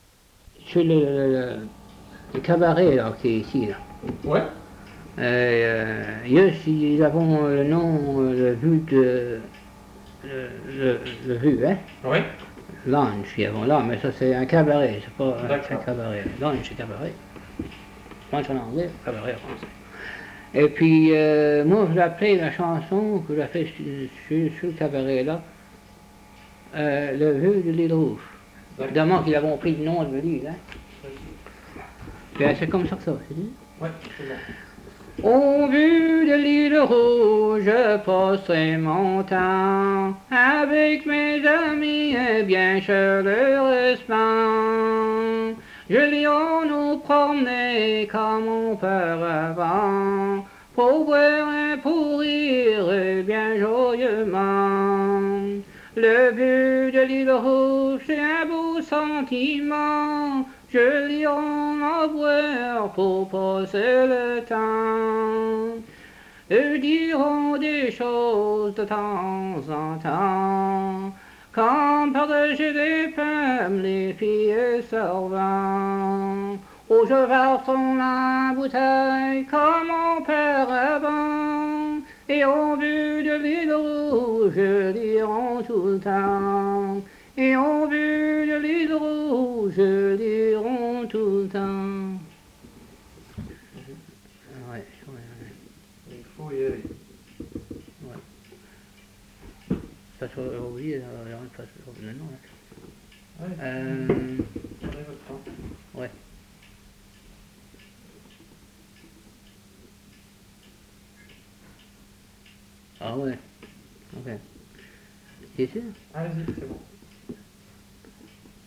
Emplacement La Grand'Terre